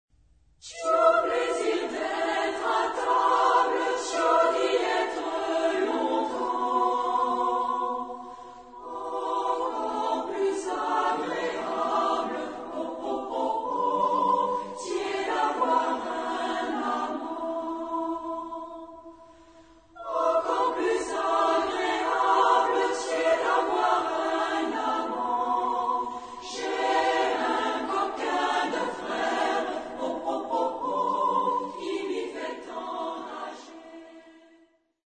Genre-Style-Form: Popular ; Children ; Secular
Type of Choir: SAA OR SSA  (3 children OR women voices )
Tonality: G major
Origin: Poitou (F)